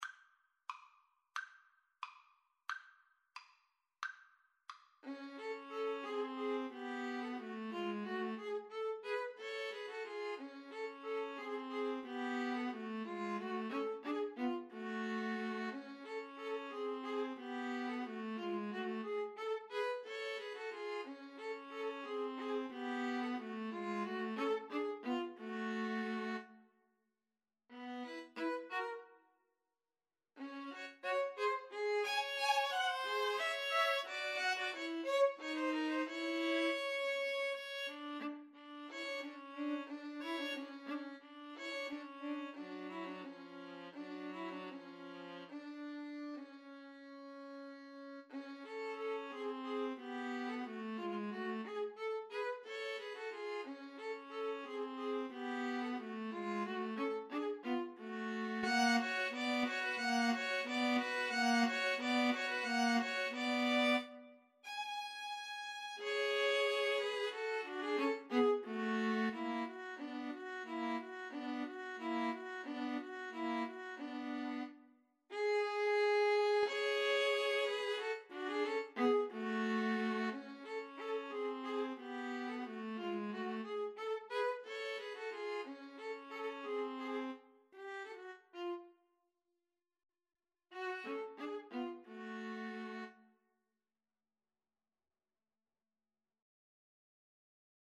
Allegretto = 90
Classical (View more Classical String trio Music)